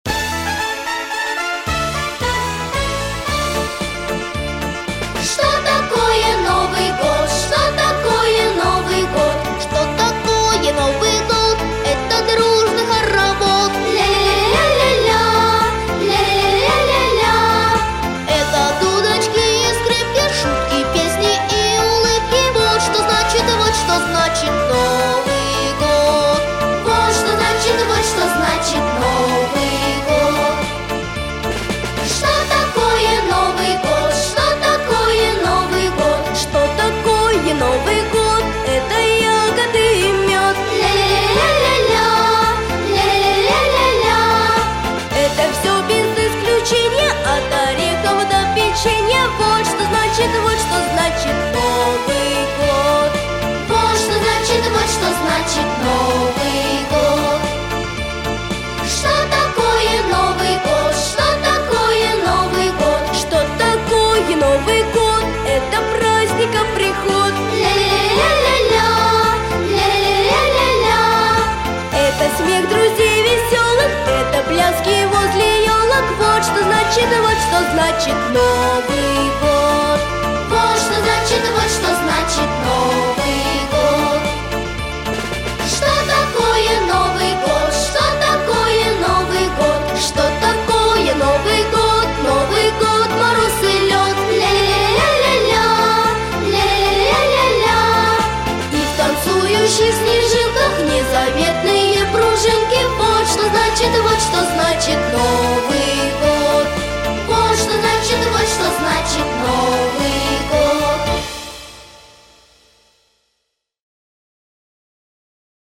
• Категория: Детские песни
Песни на Новый год 🎄